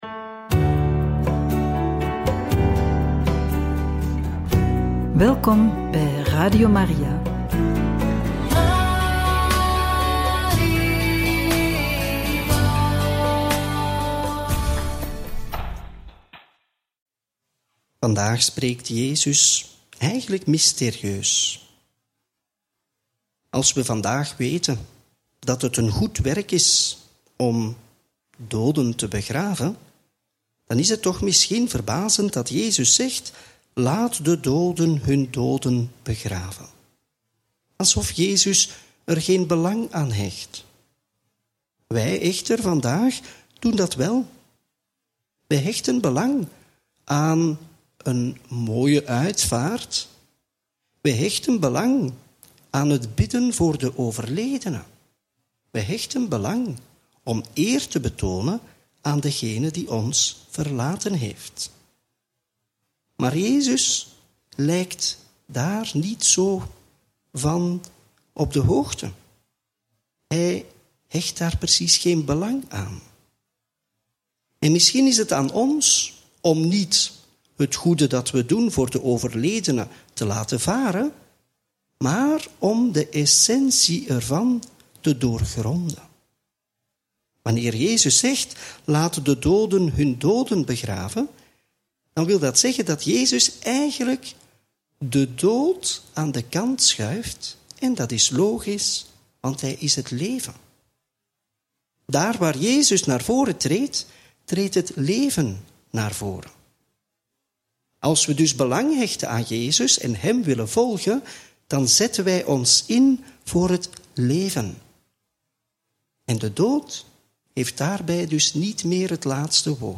Homilie bij het Evangelie op maandag 1 juli 2024 (Mt. 8, 18-22)